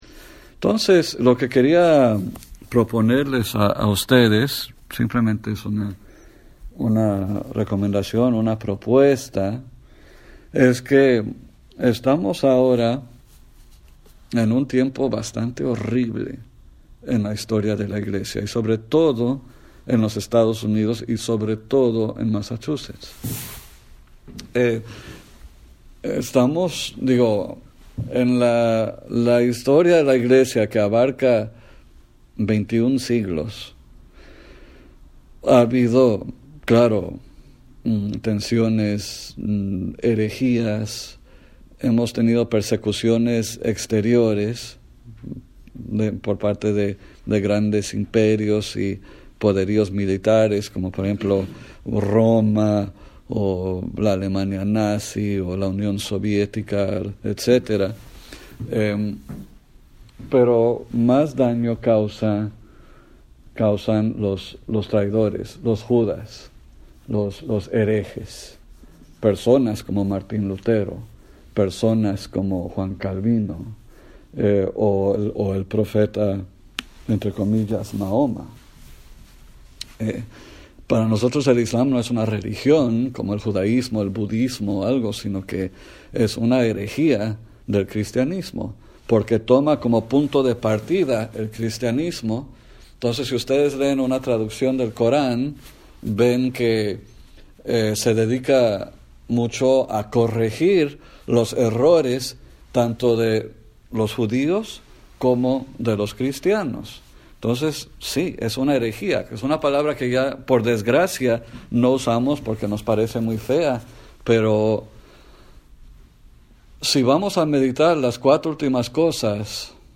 Retiro de la Guardia de Honor (El Adviento y la Penitencia)